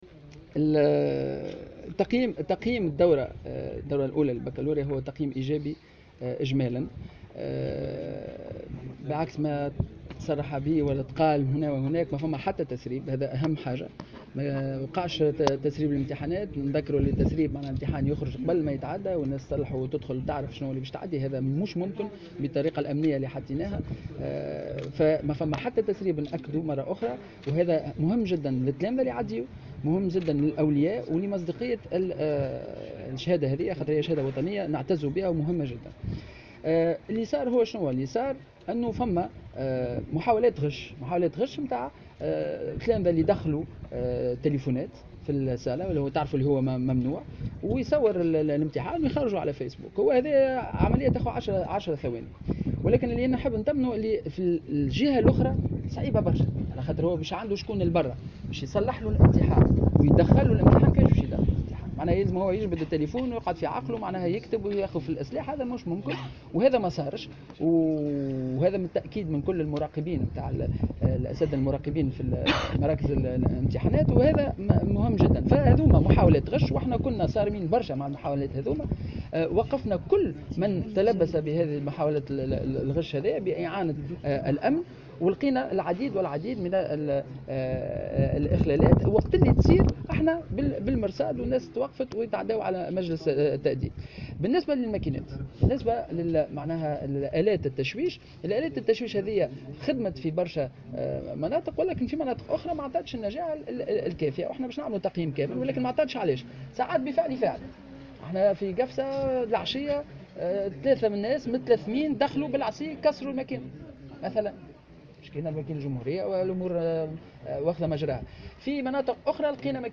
نفى وزير التعليم العالي والتربية بالنيابة سليم خلبوص في تصريح لمراسلة الجوهرة "اف ام" على هامش اشرافه على اختتام الدورة الرئيسية للباكالوريا بمعهد محمود المسعدي صحة الأخبار التي تم ترويجها بخصوص تسريب عدد من امتحانات الباكالوريا قبل موعد اجرائها.